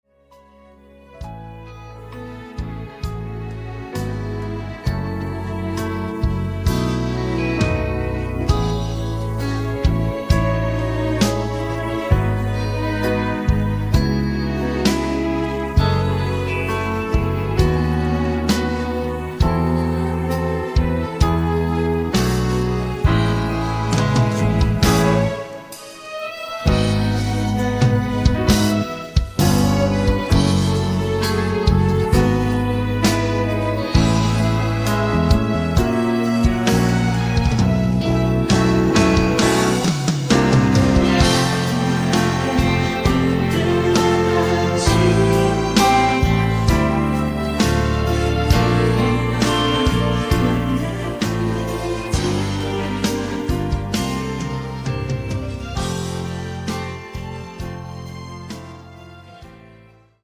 음정 원키 5:37
장르 가요 구분 Voice Cut